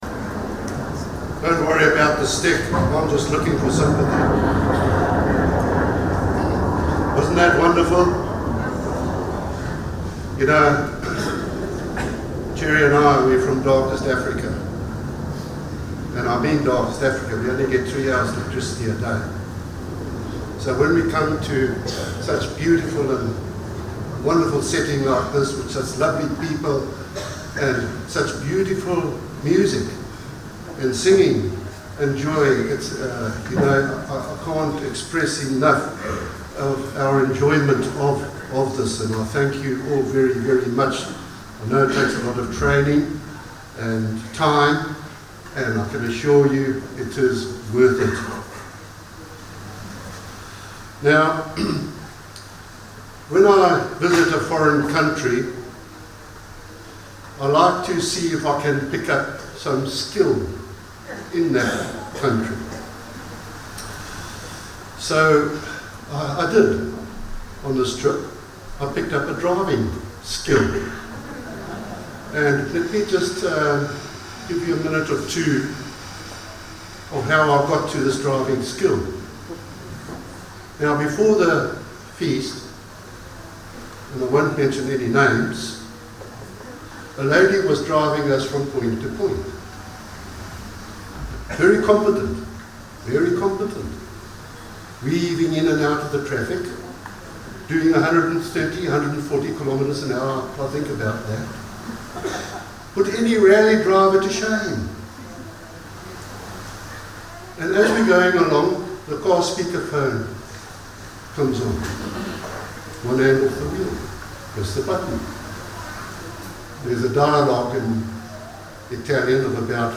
LGD 2024 Marina di Grosseto (Italy): Afternoon Service